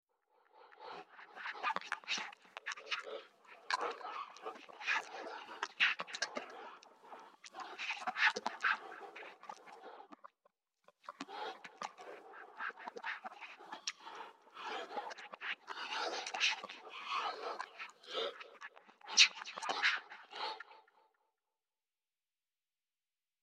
На этой странице собраны пугающие звуки призраков и привидений, которые помогут вам создать таинственную или жуткую атмосферу.
Звук бормочущего призрака, чья речь непонятна никому